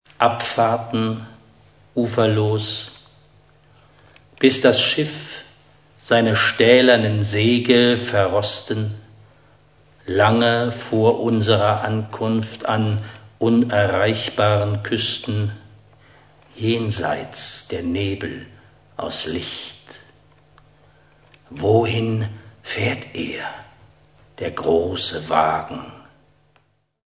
Die mit "Audioclip" gekennzeichneten mp3-Stücke enthalten kleine Ausschnitte aus dem literarisch-musikalischen Programm